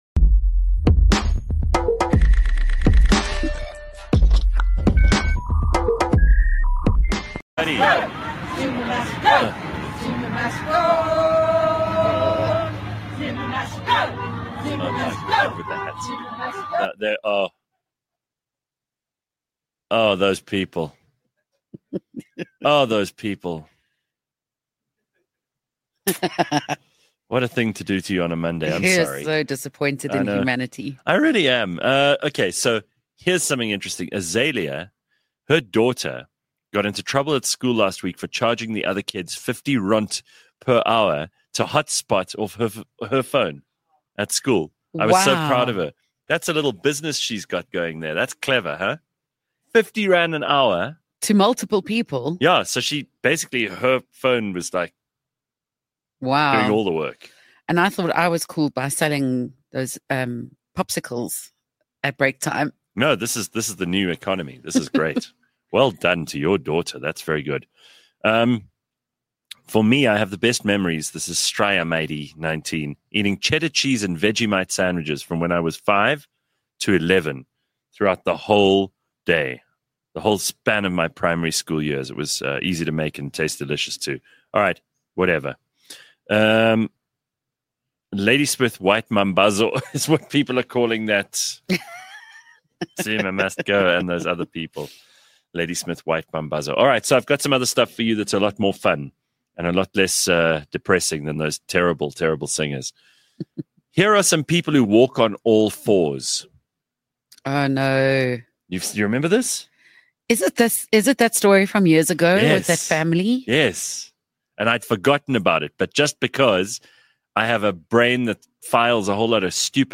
A live podcast show, that’s like a morning radio show, just much better.
Clever, funny, outrageous and sometimes very silly.